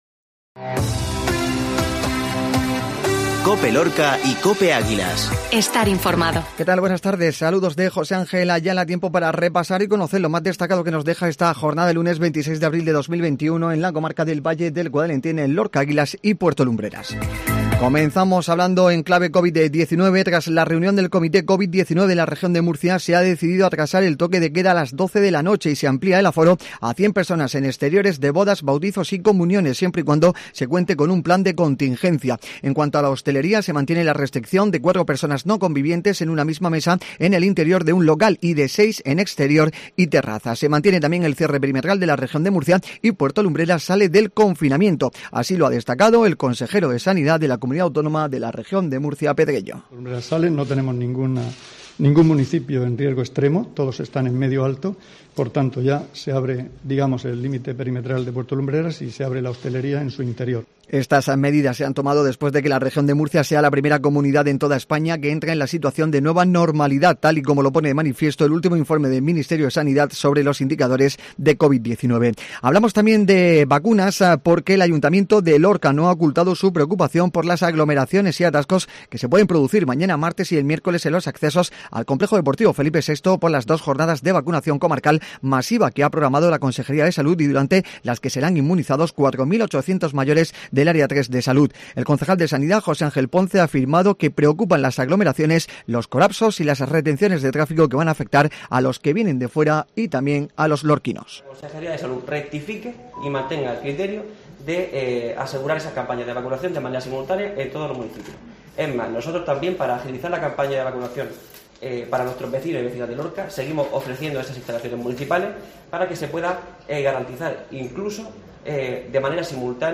INFORMATIVO MEDIODÍA LORCA